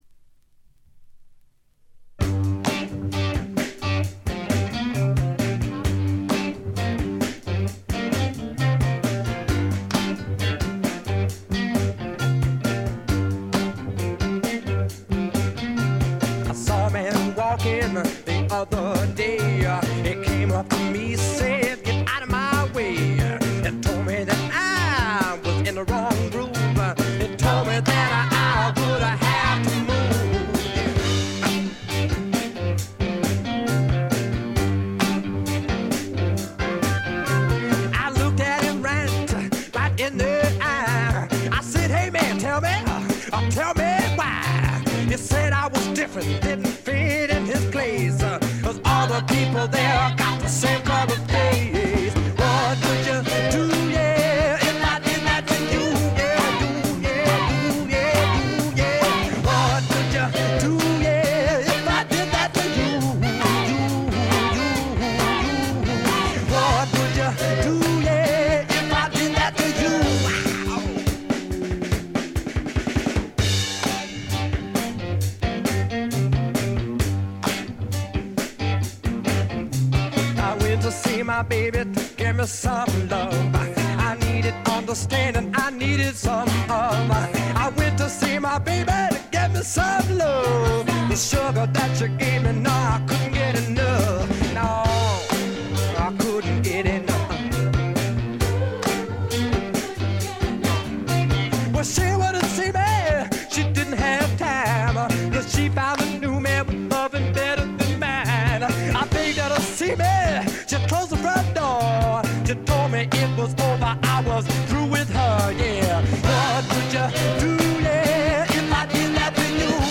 ロックとソウル境界線を行き来する傑作です。
試聴曲は現品からの取り込み音源です。